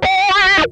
MANIC WAH 12.wav